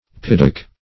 Search Result for " piddock" : Wordnet 3.0 NOUN (1) 1. marine bivalve that bores into rock or clay or wood by means of saw-like shells ; The Collaborative International Dictionary of English v.0.48: Piddock \Pid"dock\, n. [Etymol. uncertain.]
piddock.mp3